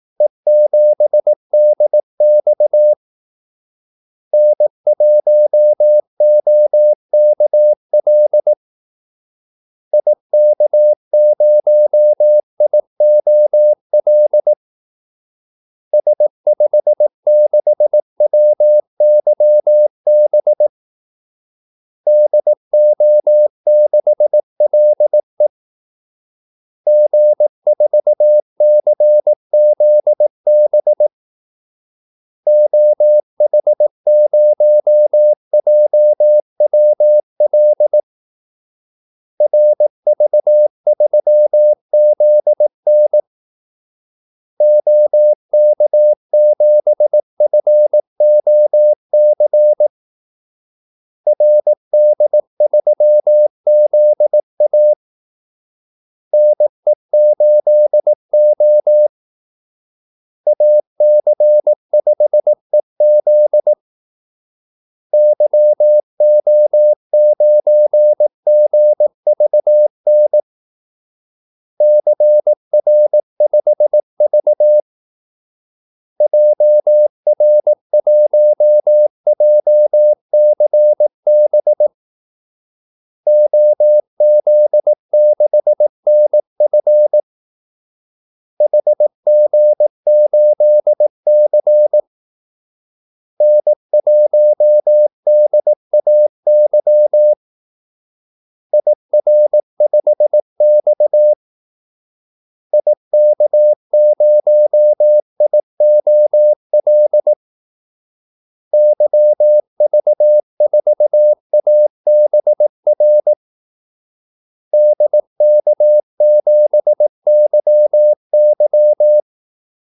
Kaldesignaler 18 wpm | CW med Gnister
Callsigns-18wpm.mp3